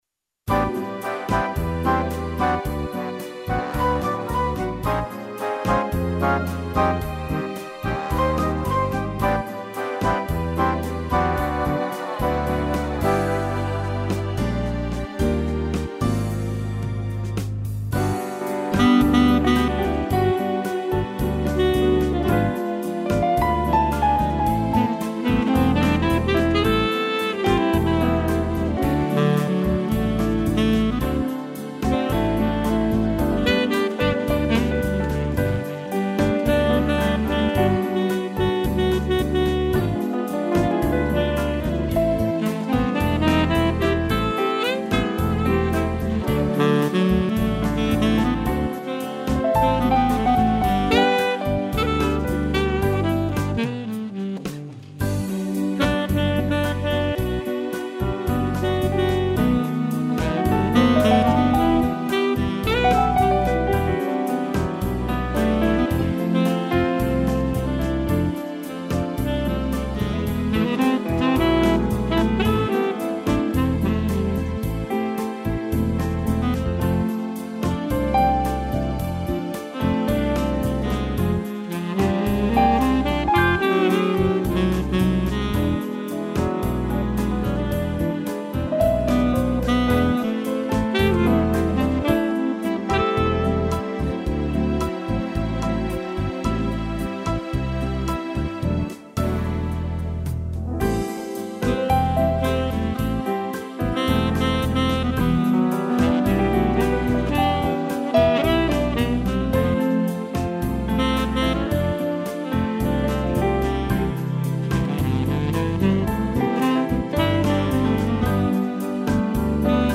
frases sax